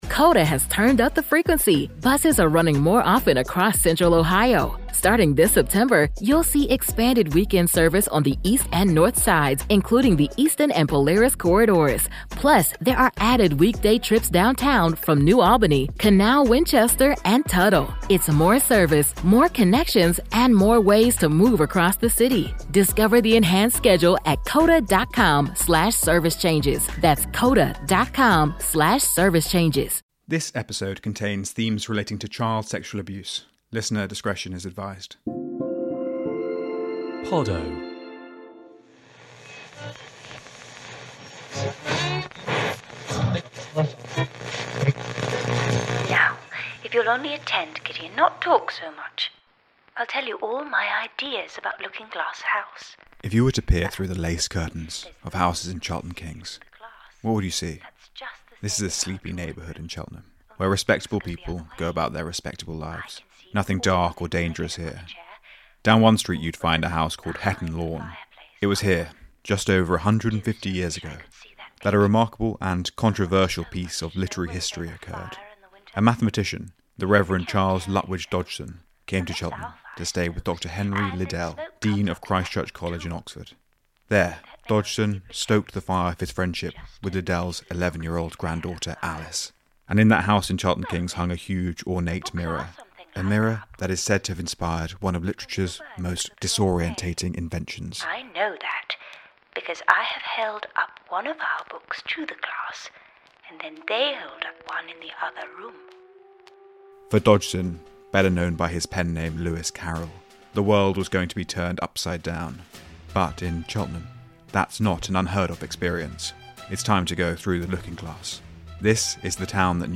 based on The Planets by Gustav Holst.